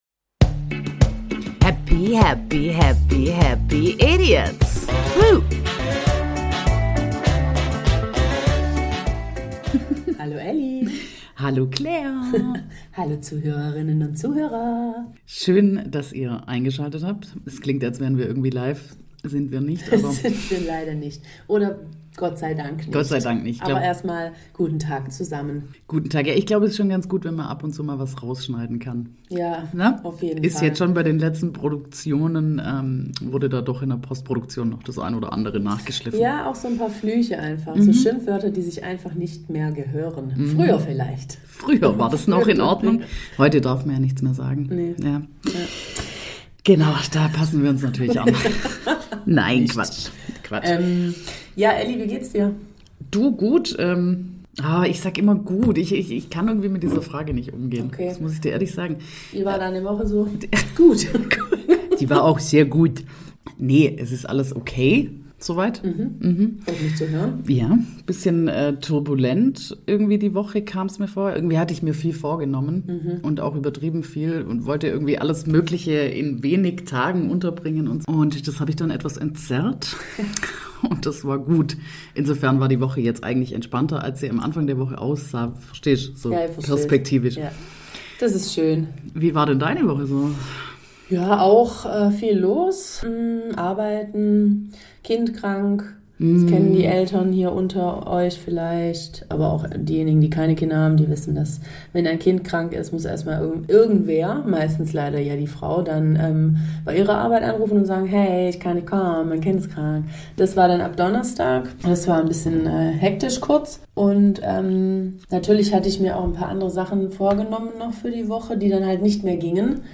Neue Eröffnungsmusik?